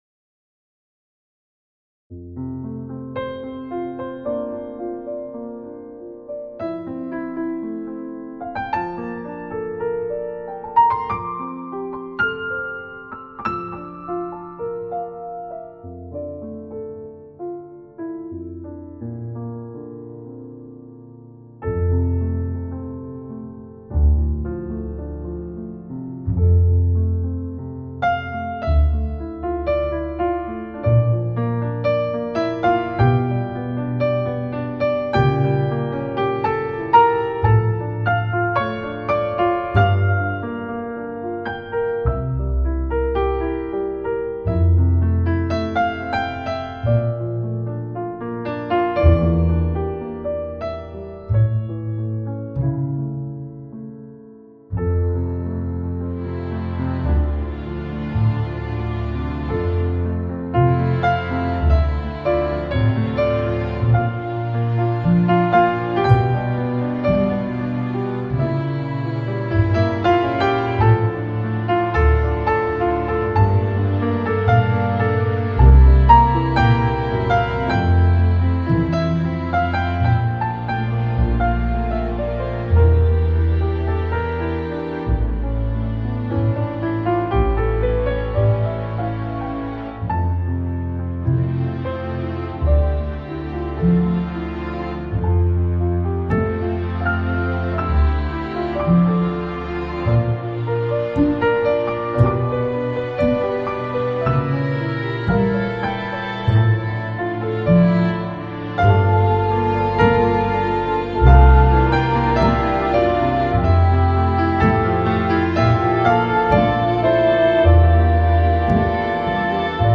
Balearic, Lounge, Chillout, Downtempo Label